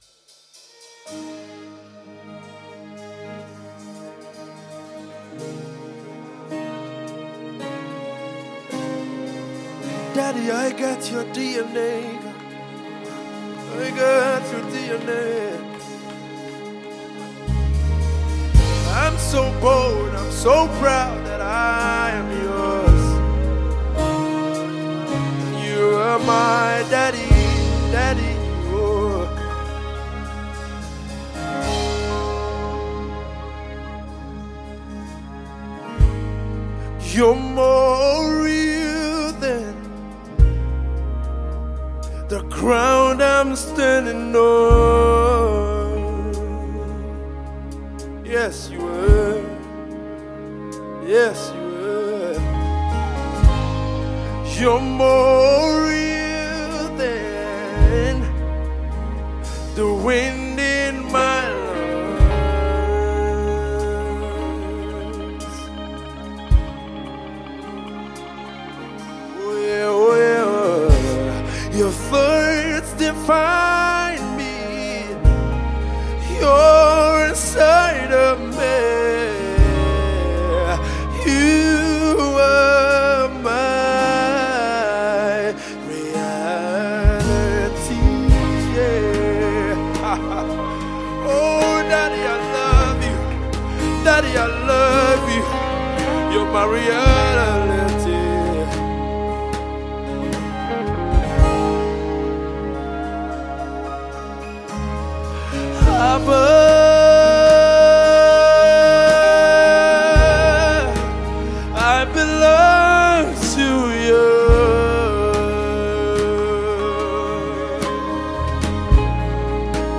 All songs recorded Live at ONE SOUND STUDIO, BADORE